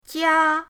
jia1.mp3